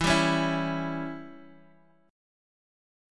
Listen to Em6 strummed